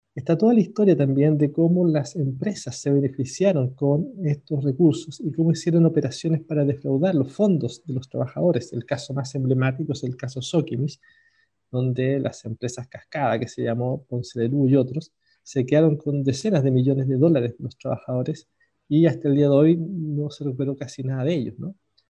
cuna-02-libro-afp.mp3